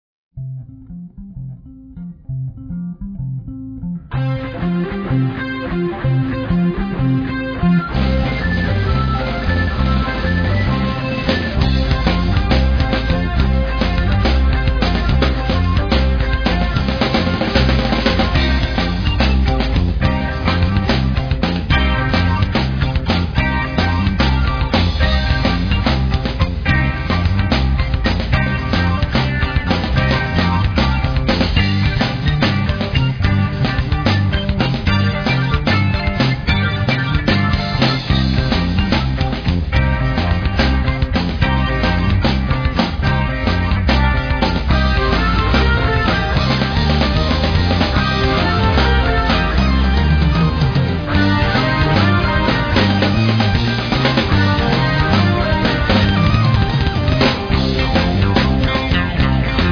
teclados
vocais em português